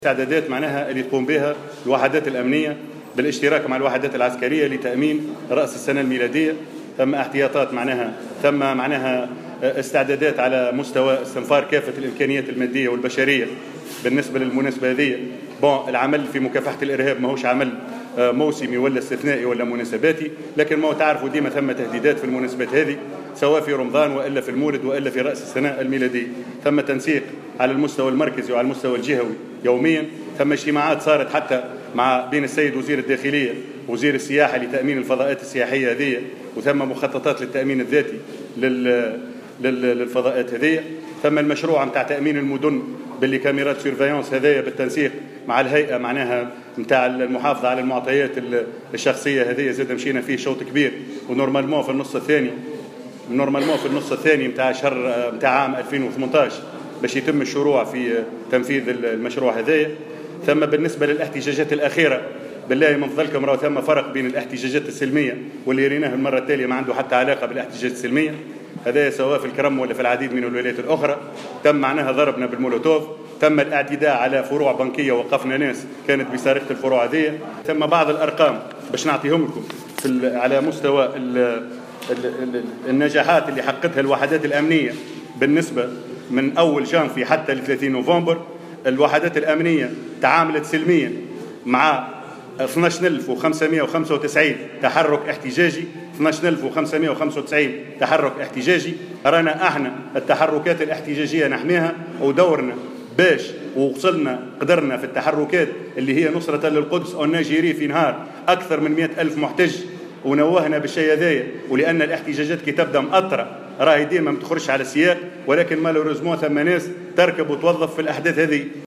في تصريح لمراسل الجوهرة اف ام، خلال ندوة صحفية اثر تدشين فضاء المواطن بوزارة الداخلية